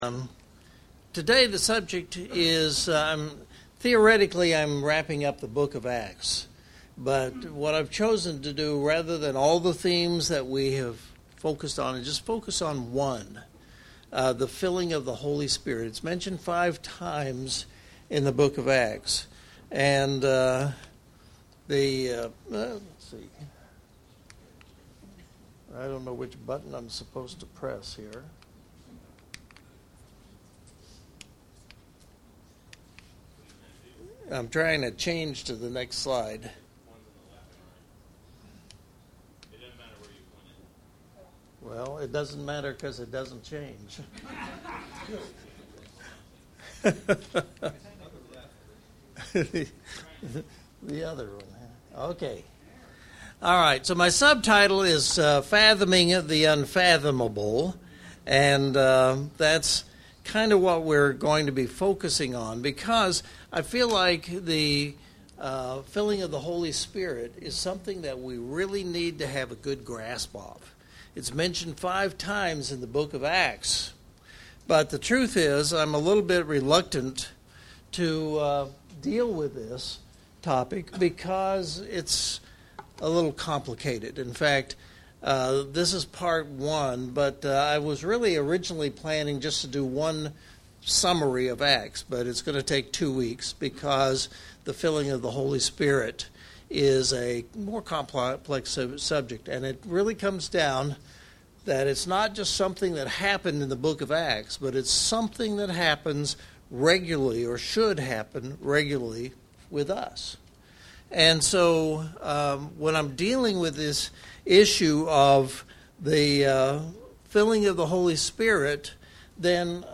Passage: Ephesians 3:14-21 Service Type: Sunday Morning « 2024 Summer Series Overview The Filling of the Holy Spirit